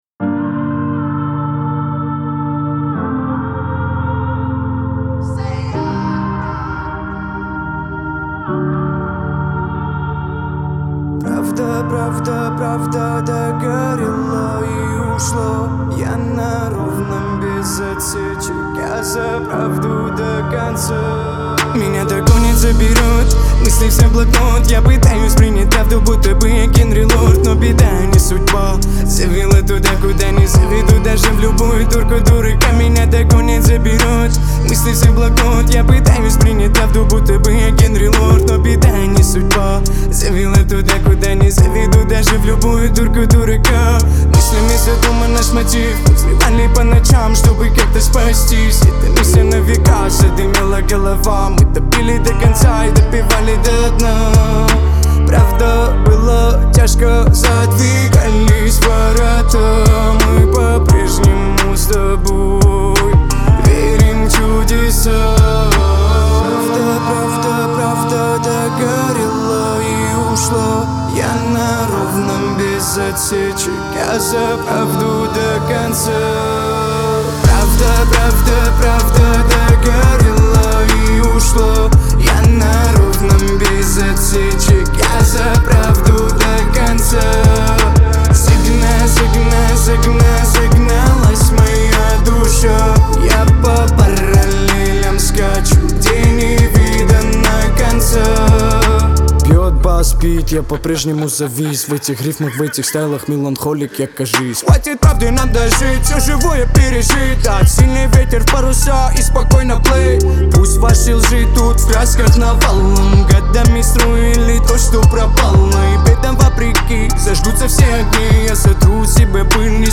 выполненная в жанре поп-рок.